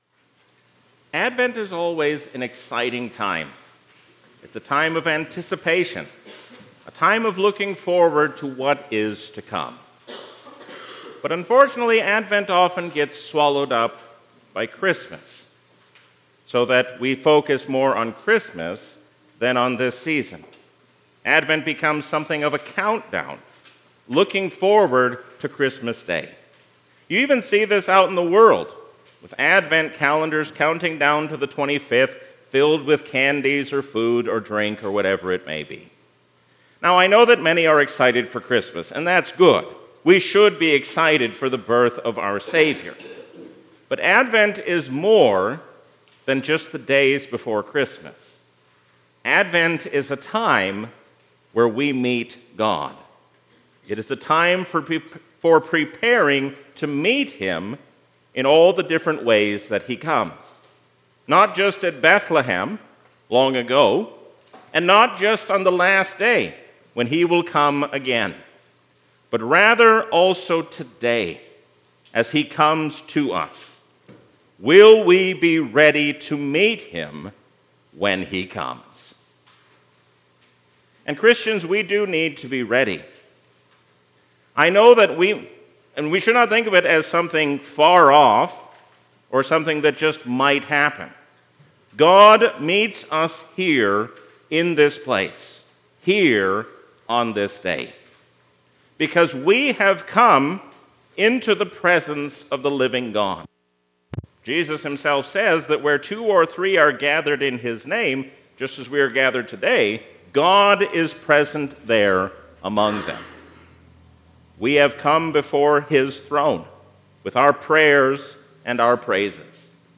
A sermon from the season "Advent 2023." We can trust all the promises of God, because we have God's favor in His Son.